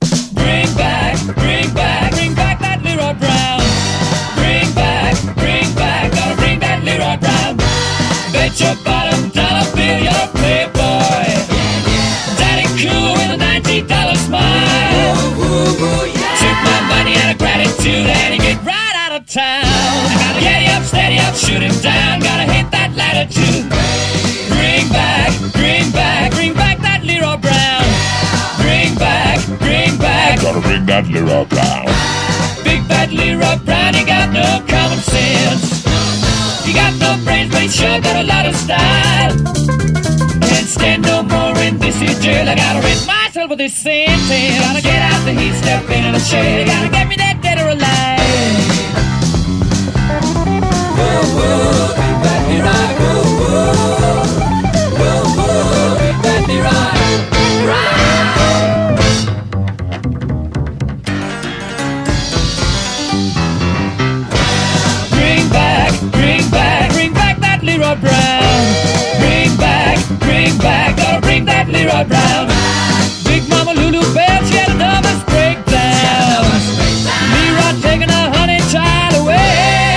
HIP HOP/R&B / OLD SCHOOL (US)